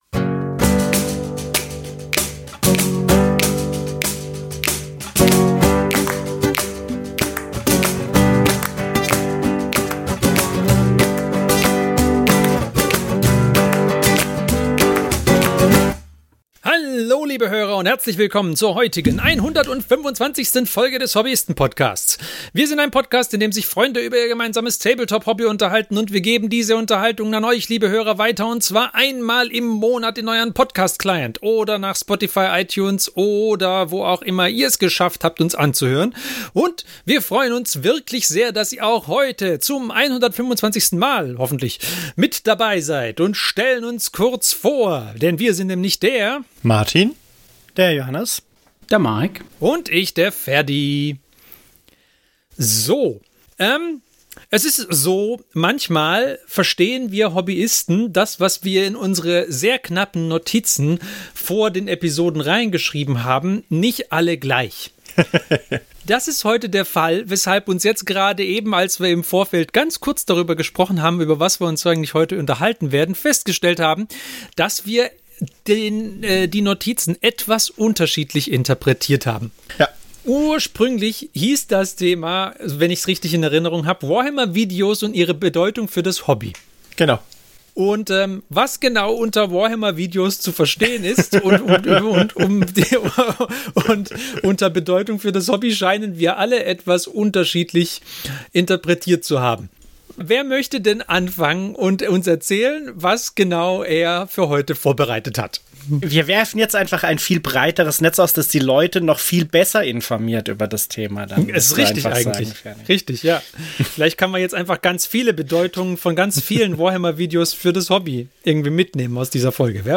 Freunde reden über Tabletop